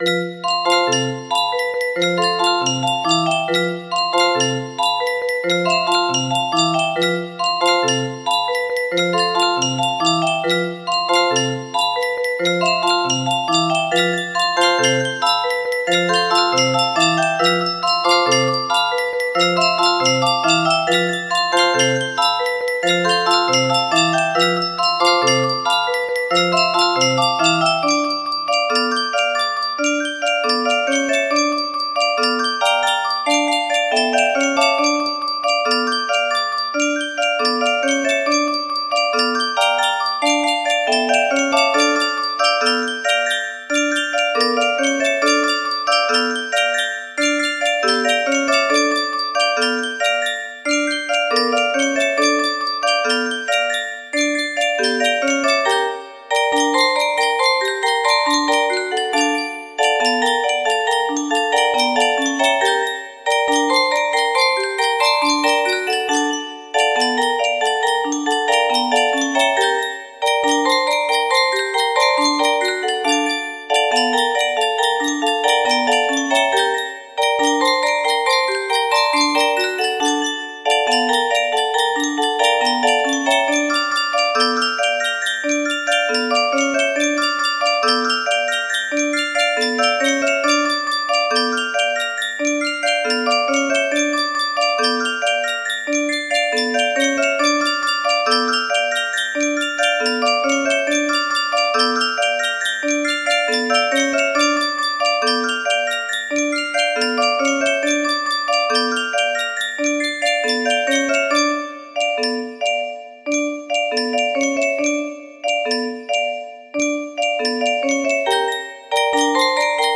Full range 60
cover